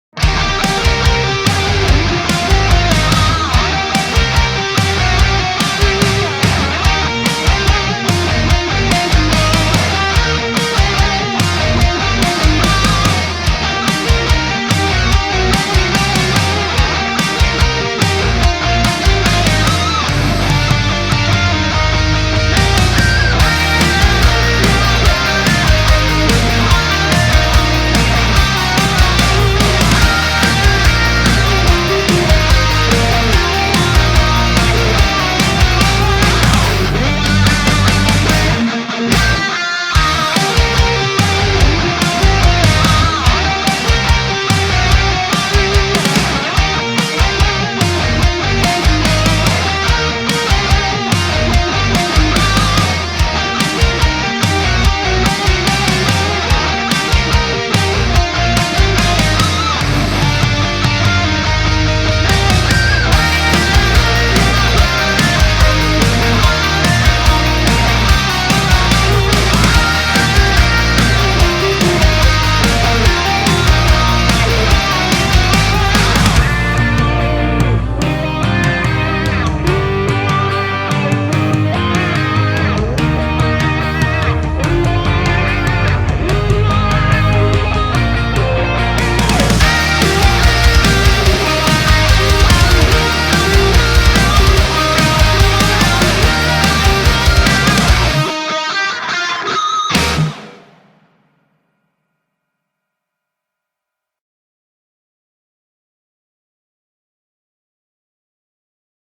Metal_Rock Guitar Cover Remix